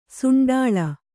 ♪ suṇḍāḷa